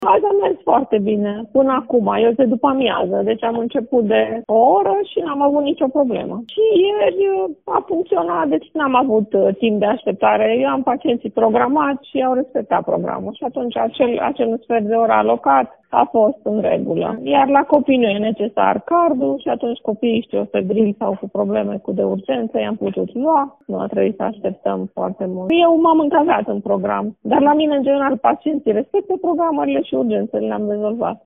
medic de familie din Tg.Mureș